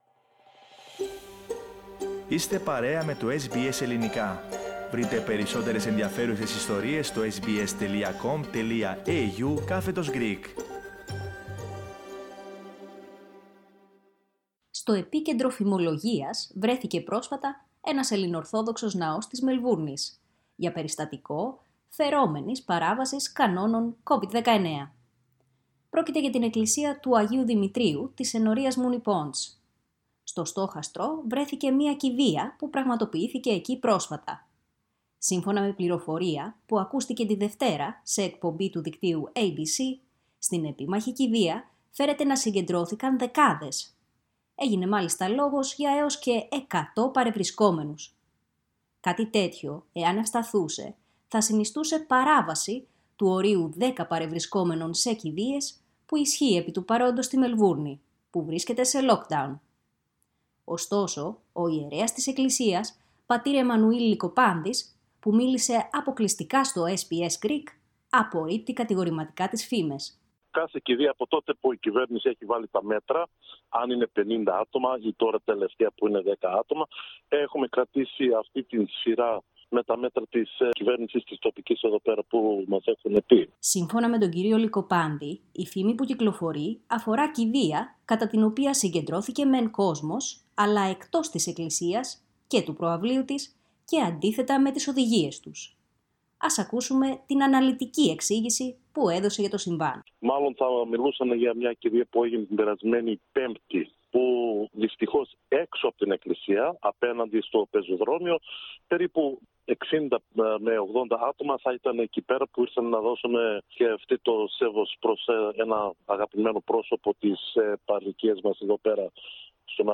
Ο ιερέας της ενορίας μιλώντας αποκλειστικά στο SBS Greek δήλωσε πως η φήμη προέκυψε μάλλον από κηδεία κατά την οποία συγκεντρώθηκε μεν κόσμος αλλά εκτός της εκκλησίας και του προαυλίου της και αντίθετα με τις οδηγίες τους.